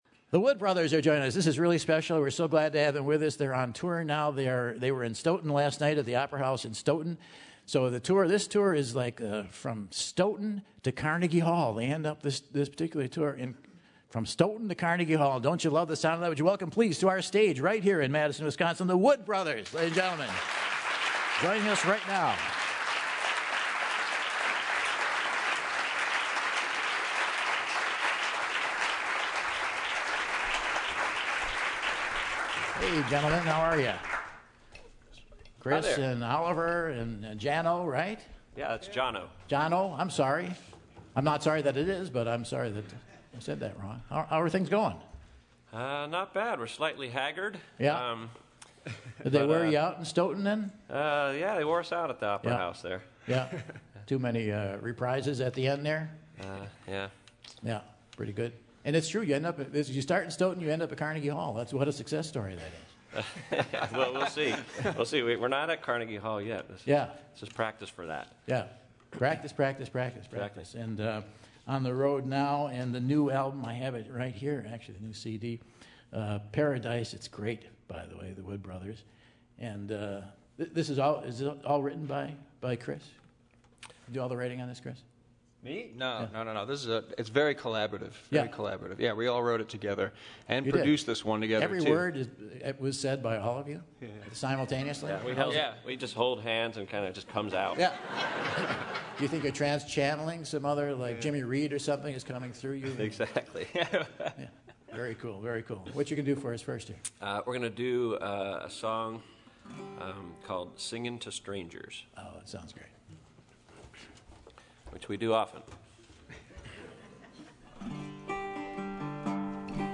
Americana
hop on the Terrace stage to play a few tunes